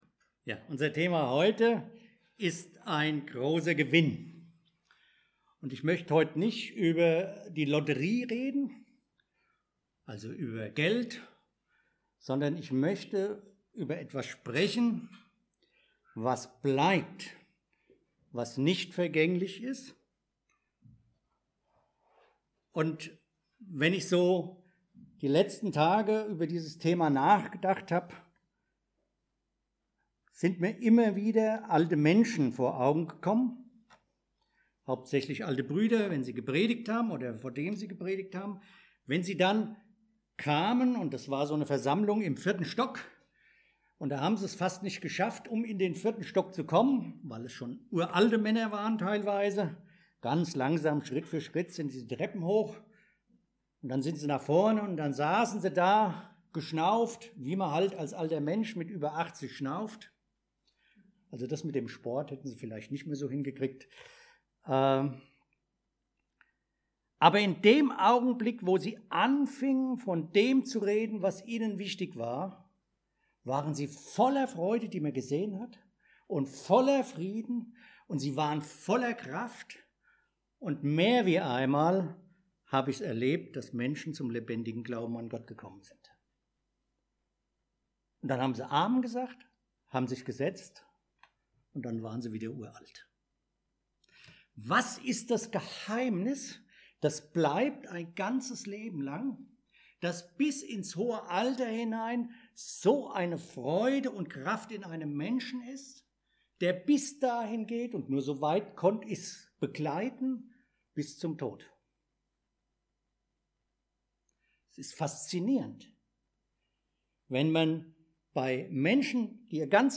1-2 Download file Veröffentlicht unter Predigt Nachahmer Gottes Veröffentlicht am 9.